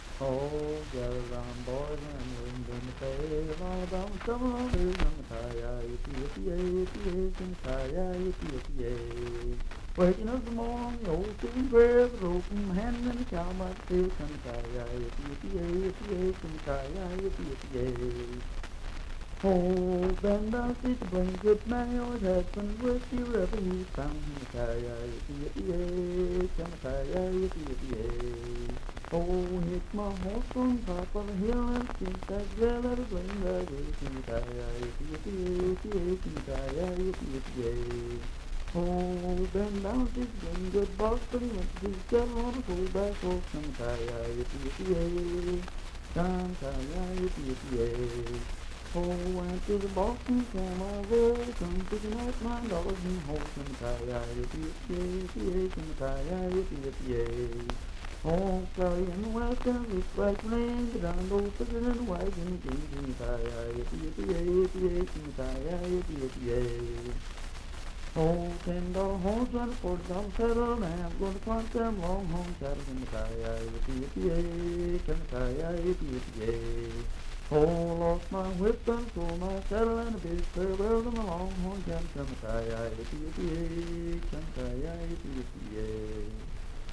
Unaccompanied vocal music
Verse-refrain 9(4w/R).
Voice (sung)
Pendleton County (W. Va.), Franklin (Pendleton County, W. Va.)